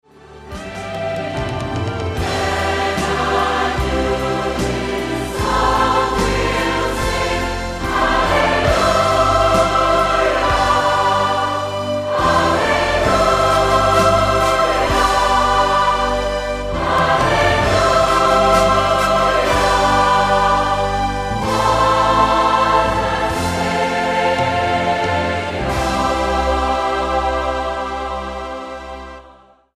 STYLE: MOR / Soft Pop
300 singers from over 90 churches in Central Scotland